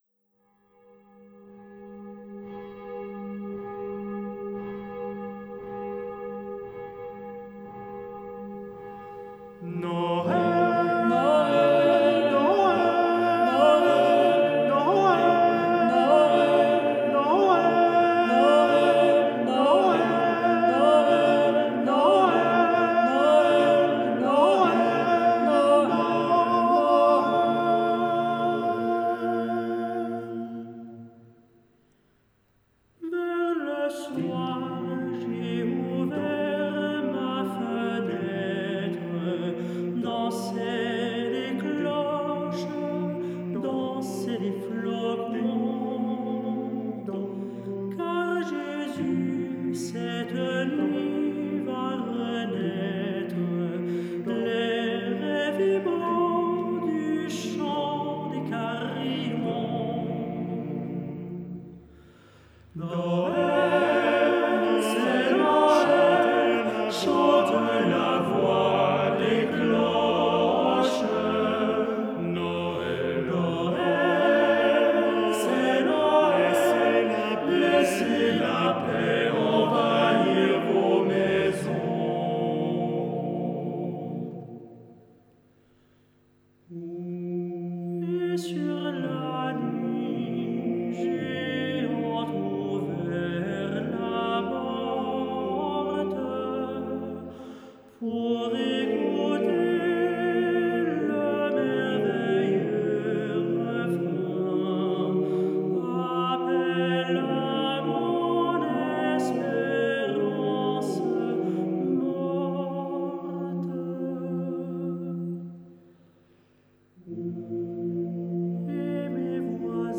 pour quatre voix d’hommes